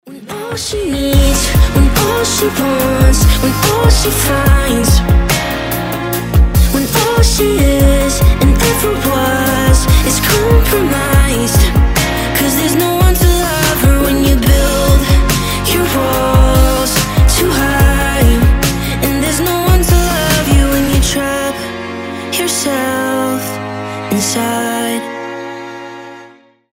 • Качество: 320, Stereo
красивый мужской голос